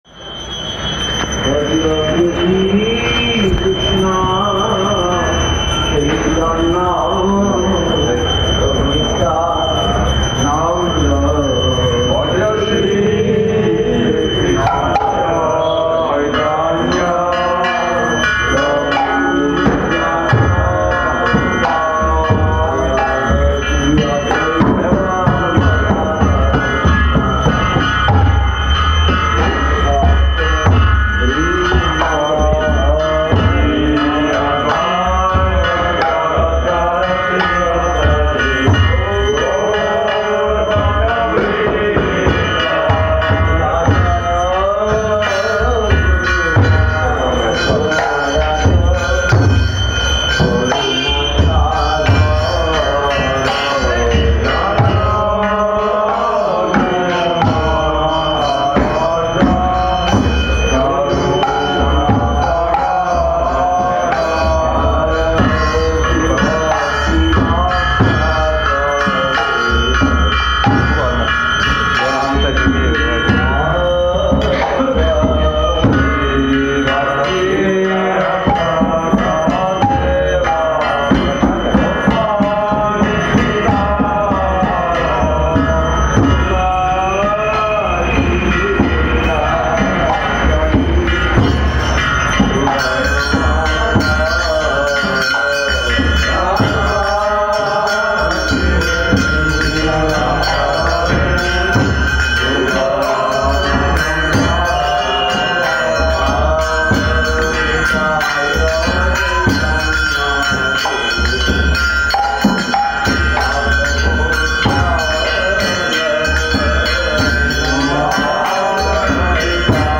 Gaura Purnima Parikrama 2012
Place: SCSMath Puri
Tags: Kirttan